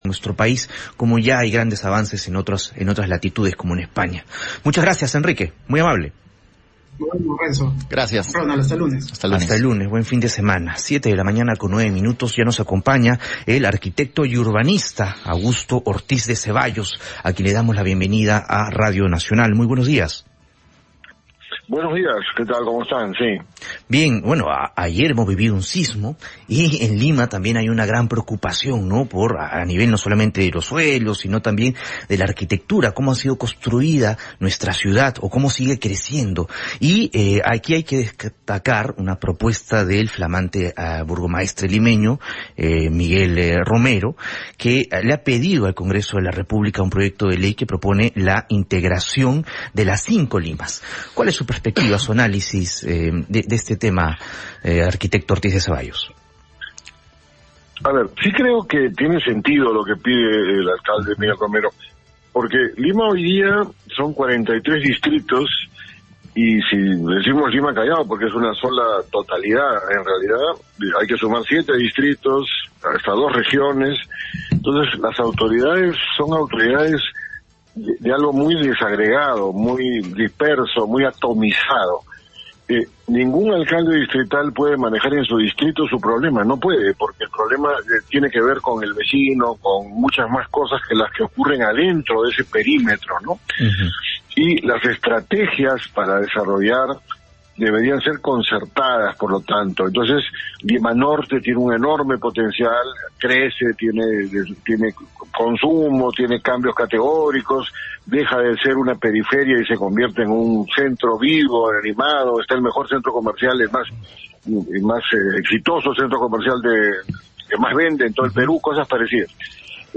Entrevista al arquitecto y urbanista, Augusto Ortiz de Zevallos